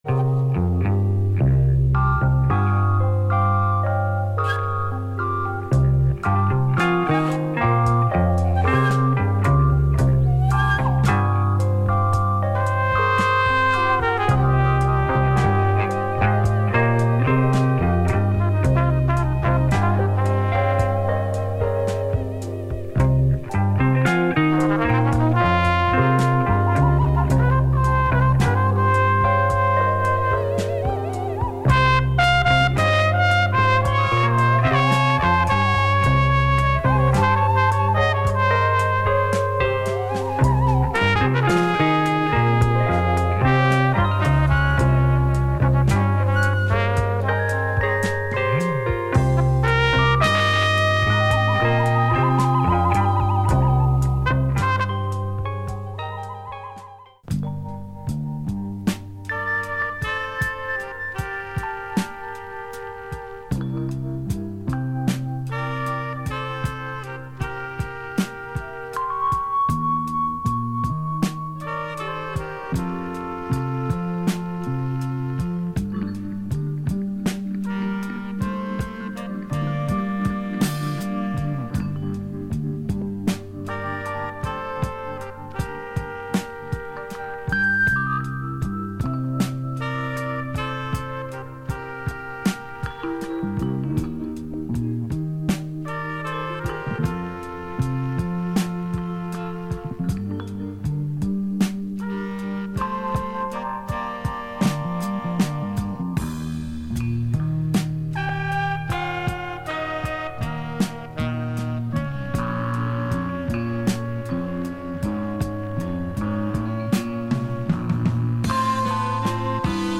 British abstract jazz